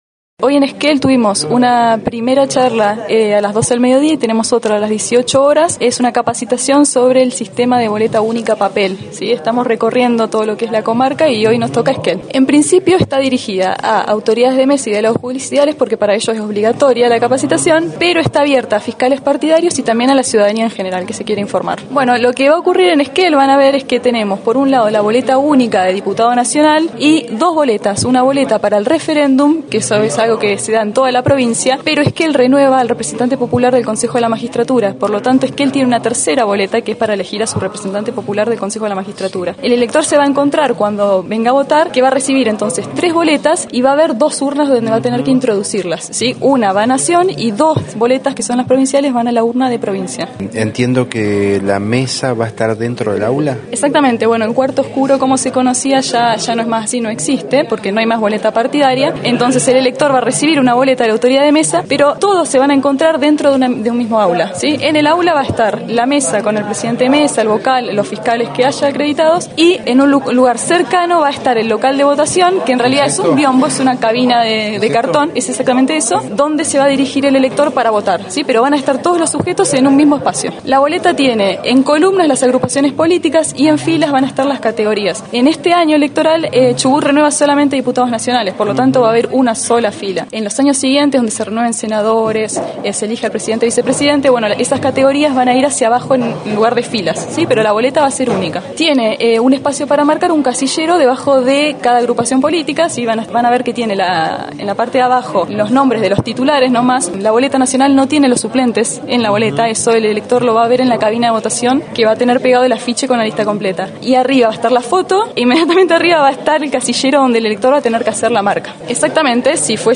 En la jornada del miércoles, se desarrollaron en Esquel, las capacitaciones obligatorias para todas las Autoridades de Mesa (Presidentes y Vocales), así como para Delegados de la Justicia Nacional Electoral, las cuales son abiertas a la ciudadanía en atención a la implementación de la Boleta Única de Papel (BUP). En diálogo con Noticias de Esquel